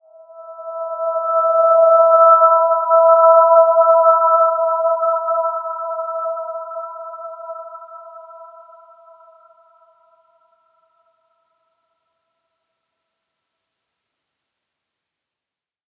Dreamy-Fifths-E5-p.wav